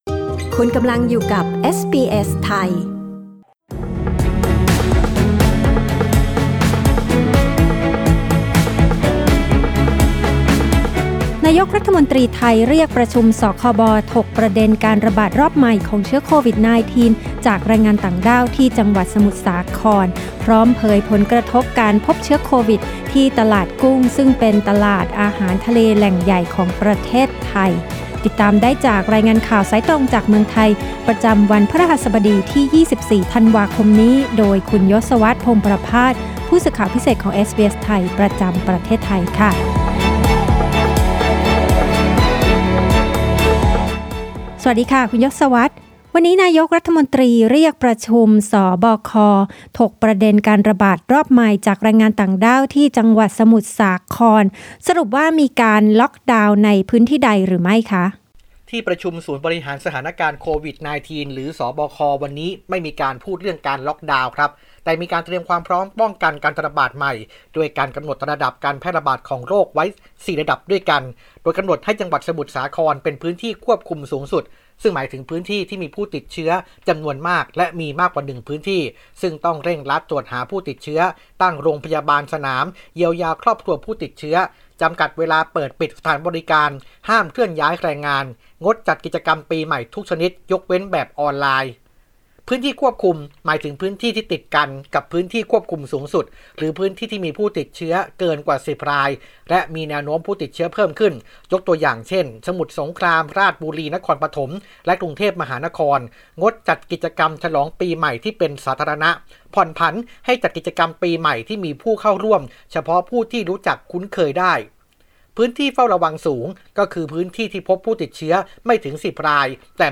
รายงานข่าวสายตรงจากเมืองไทย โดยเอสบีเอส ไทย Source: Pixabay